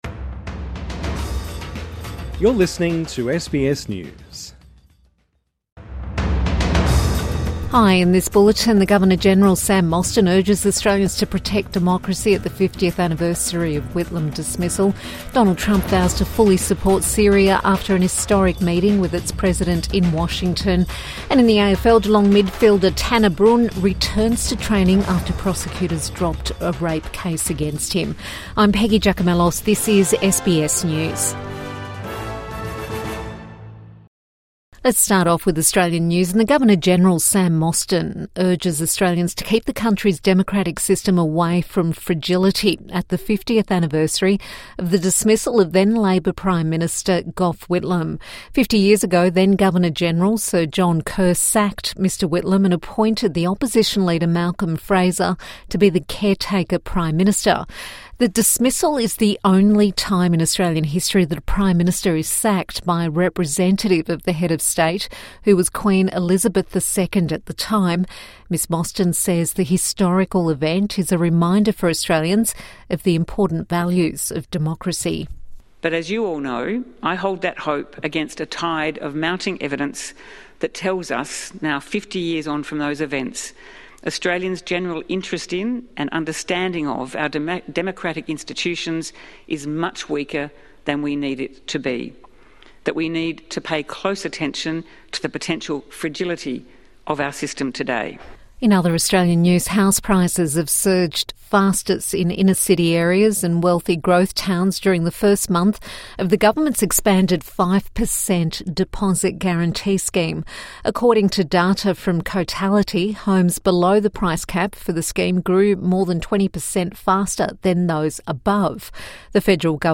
Trump meets with Syria's President |Evening News Bulletin 11 November 2025